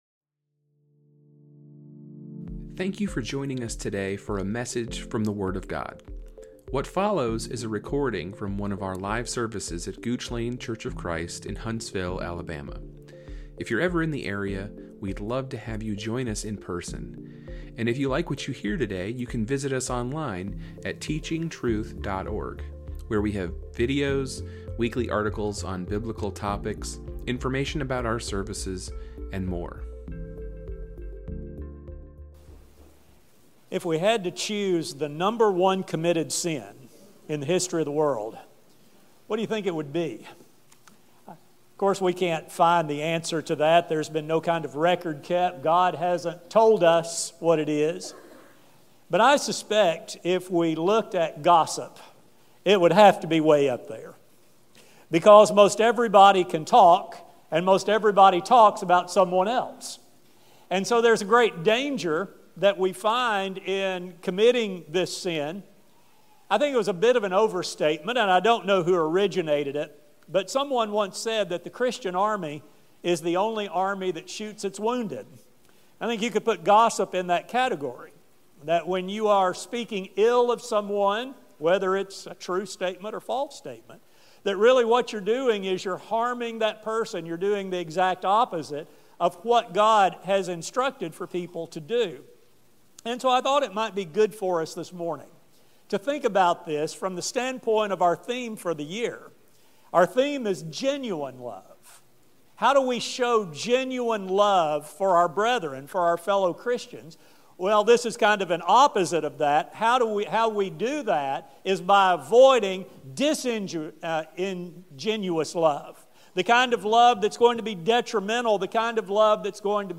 Throughout both the Old and New Testaments, God plainly expresses His displeasure for this verbal assault on others and warns of the dangers it poses. This study will explore God’s warnings on the matter and offer encouragement for the people of God to remove this danger from their lives. A sermon